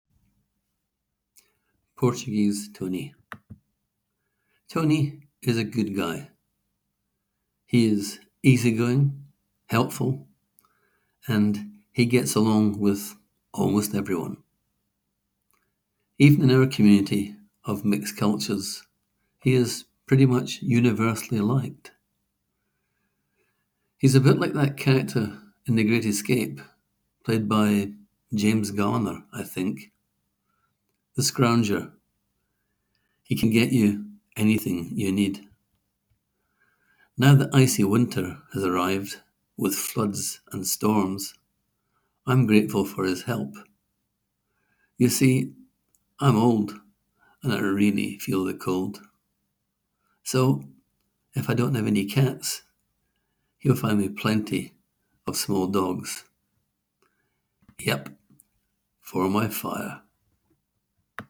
Click here to hear the author read his words: